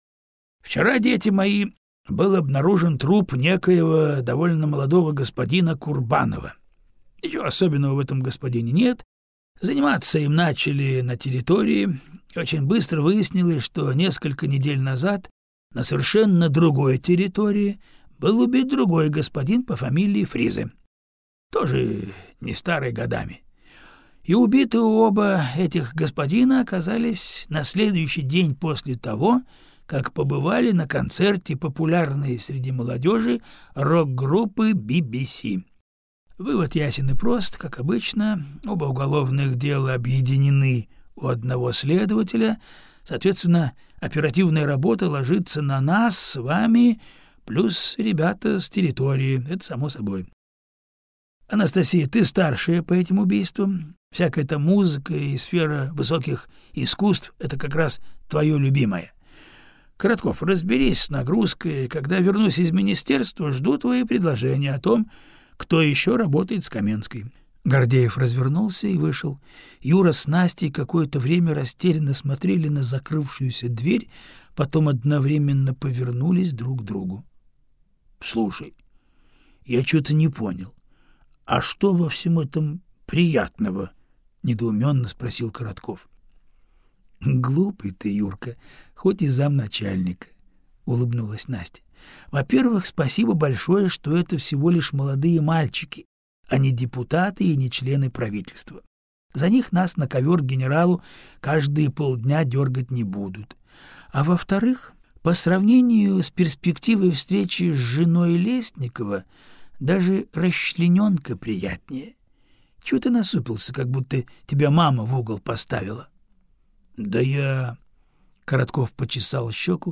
Аудиокнига Когда боги смеются - купить, скачать и слушать онлайн | КнигоПоиск